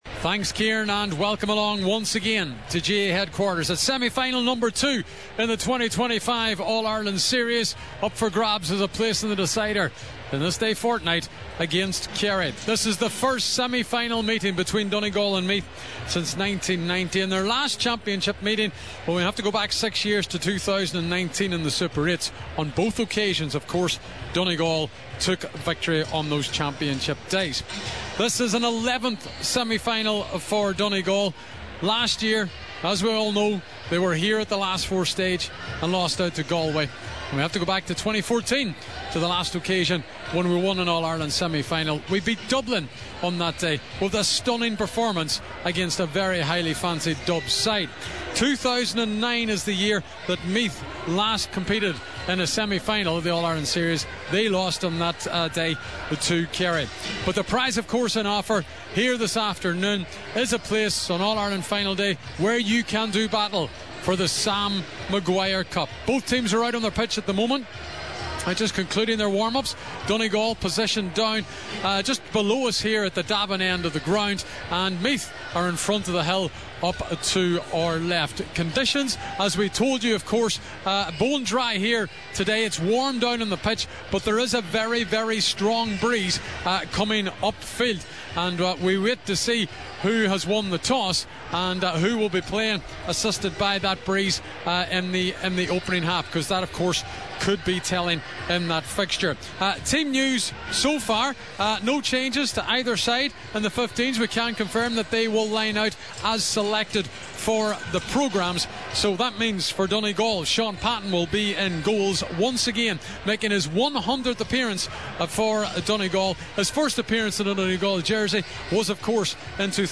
Relive Donegal’s exhilarating All Ireland Semi Final victory – Listen back to Highland’s commentary